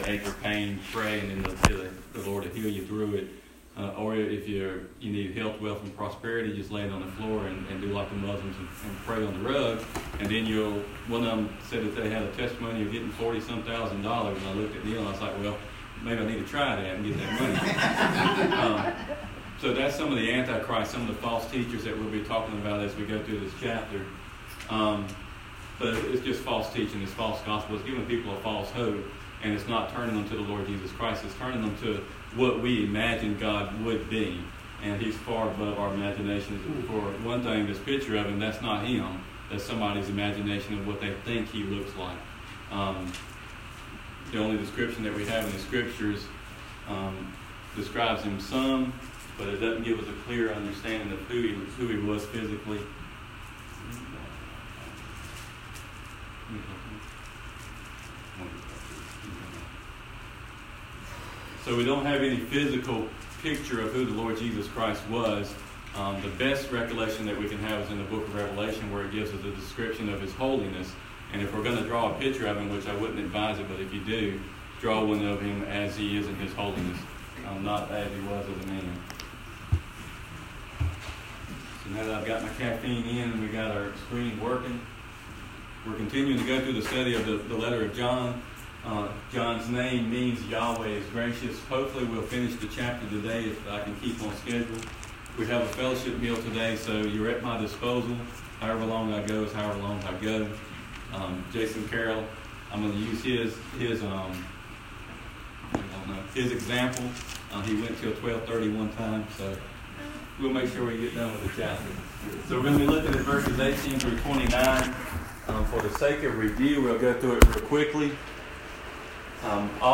1 John 2 Service Type: Sunday Morning Bible Text